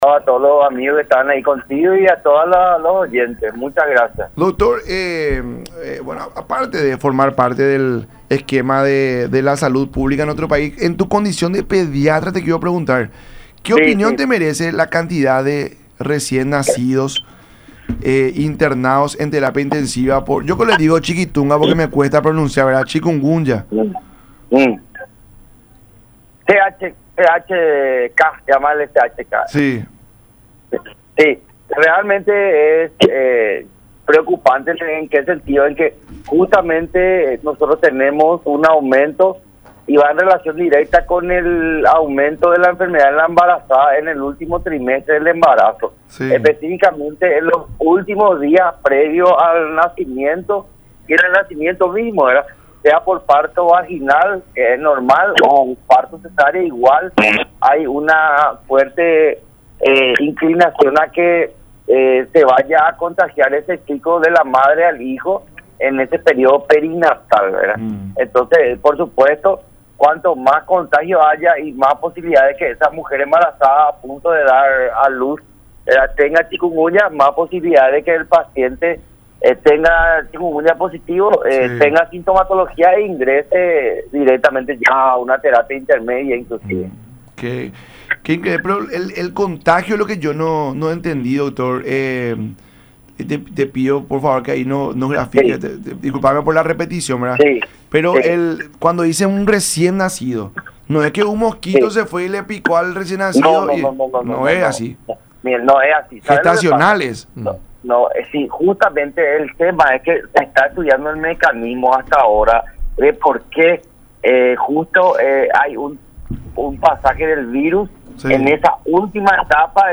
en diálogo con La Mañana De Unión por Unión TV y radio La Unión